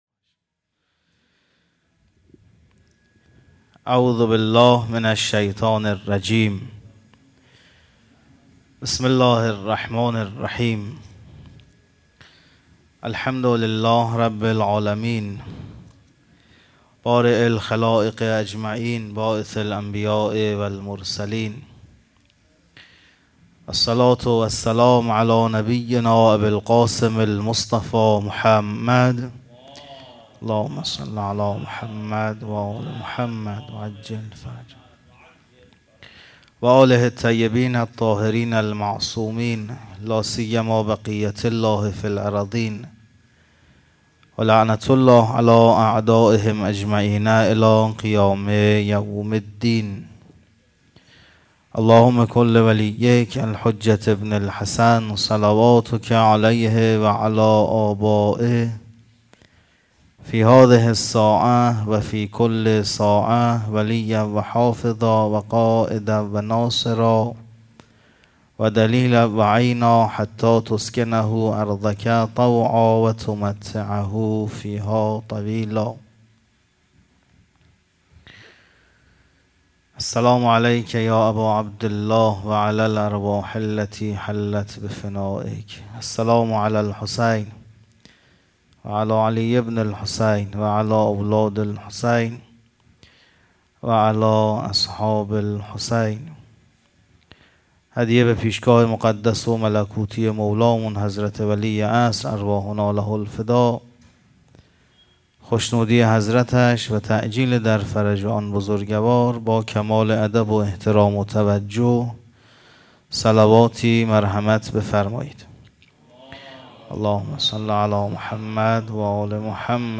مراسم هفتگی/8آذر97
سخنرانی